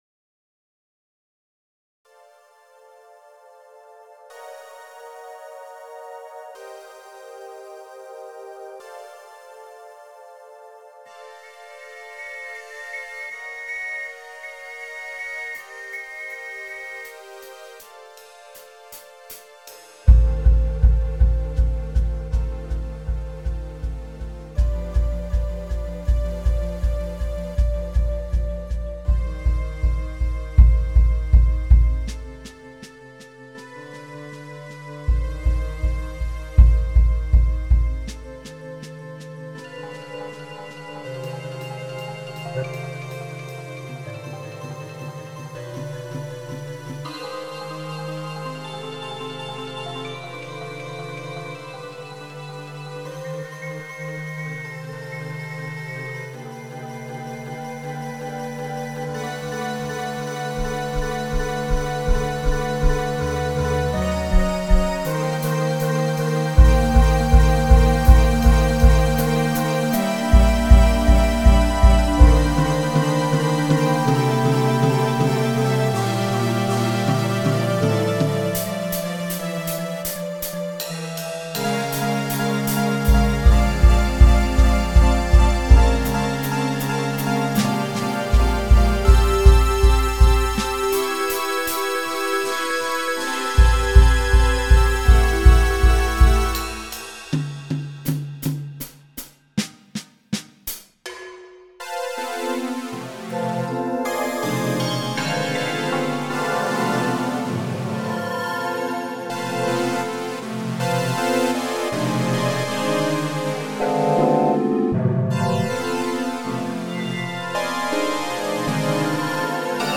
Silly experiment - Electronic
I was trying to see if I could write something musical just by copying and pasting a simple motif into different synth types available on MuseScore, and offsetting the rhythms slightly.